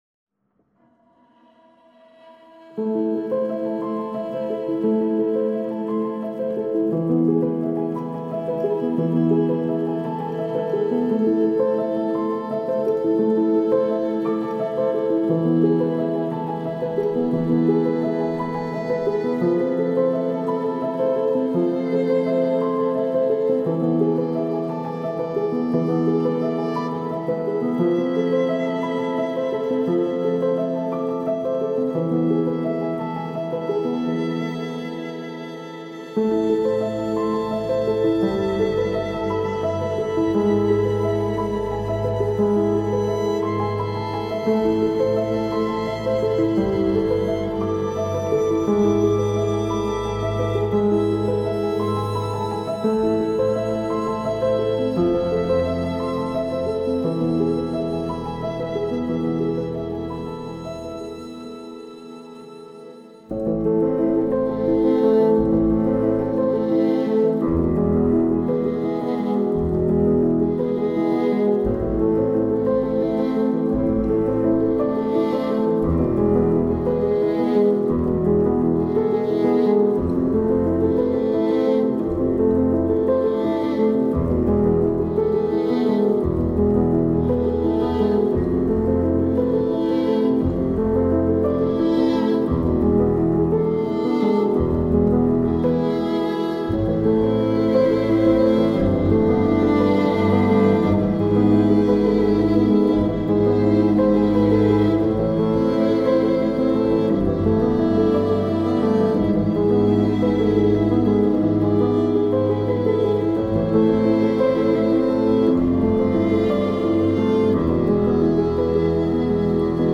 پیانو , عصر جدید , غم‌انگیز , ویولن